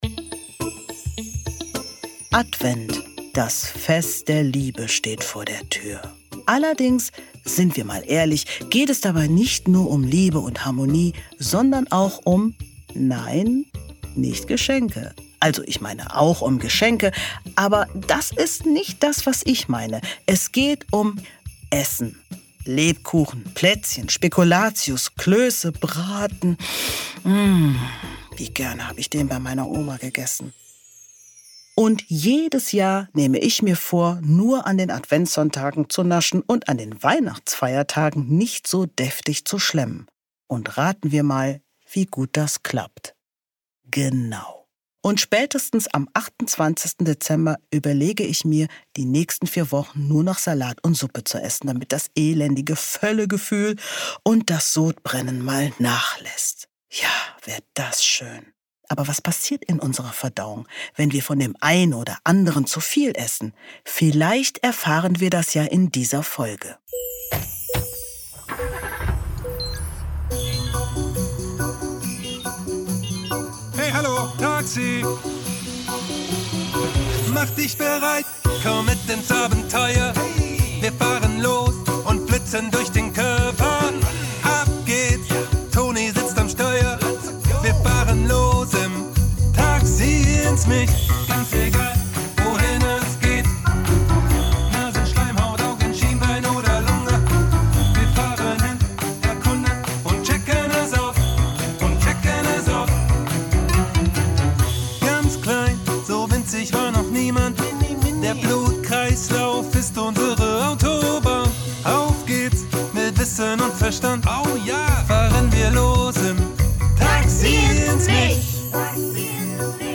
Jedes Böhnchen gibt ein Tönchen! ~ Taxi ins Mich | Der Hörspiel-Podcast für Kinder Podcast